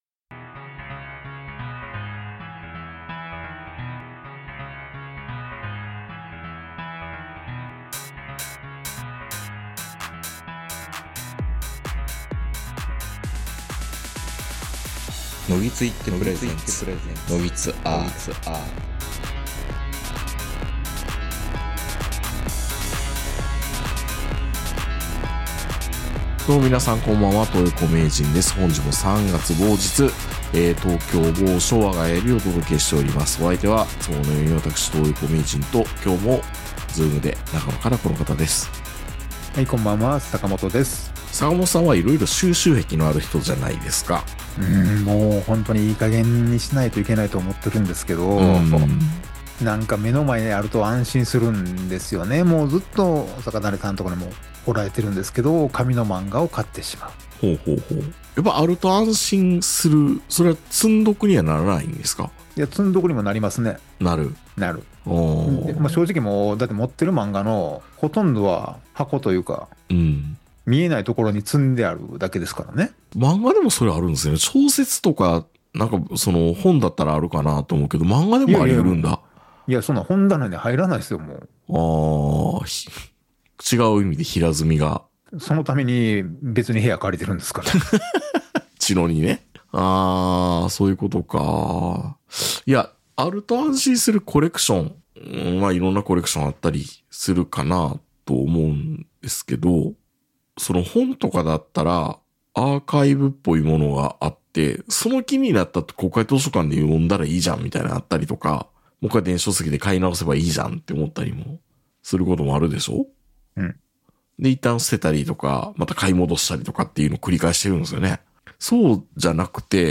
・何故かPL学園の校歌を歌う・内田康夫・山村美紗・西村京太郎・浅見光彦記念館はあるよ！